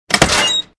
CHQ_door_open.ogg